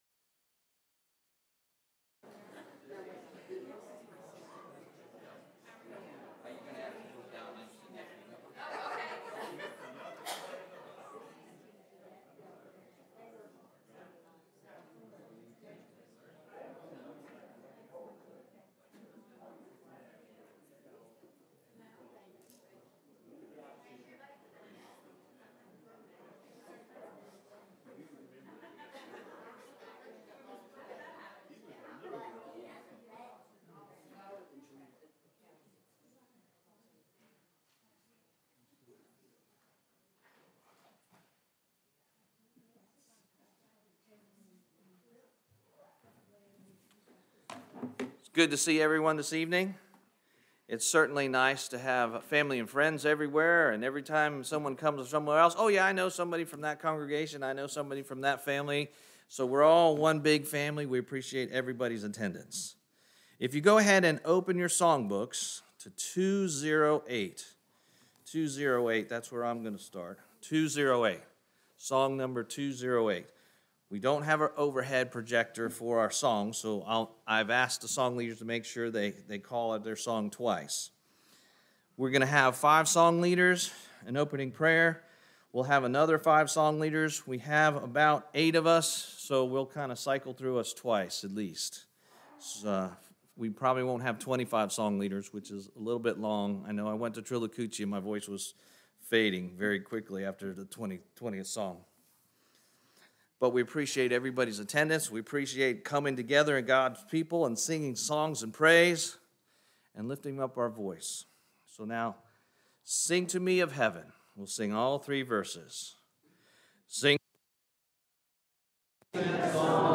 Quarterly sing February 23 2024